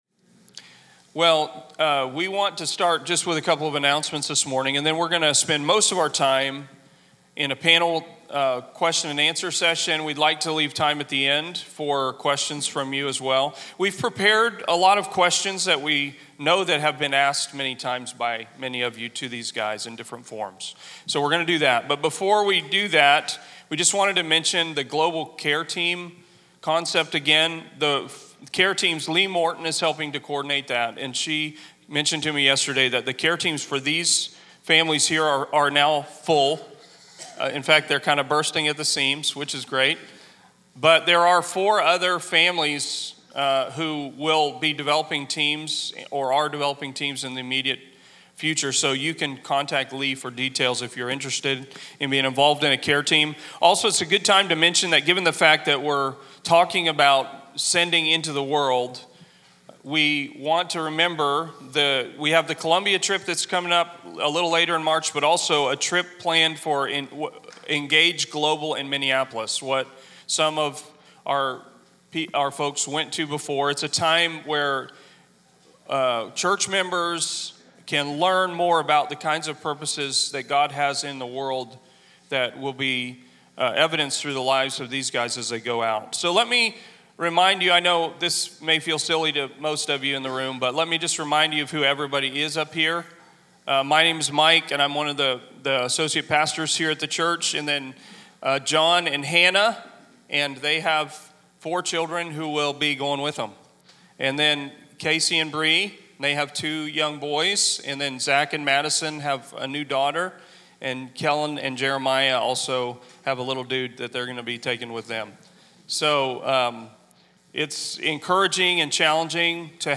TELL | Question & Answer Session